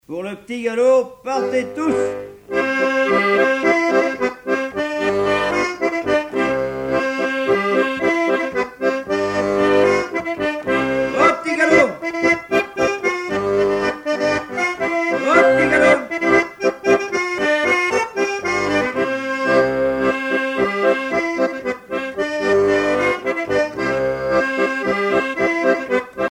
danse : quadrille : petit galop
Pièce musicale éditée